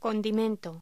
Locución: Condimento
voz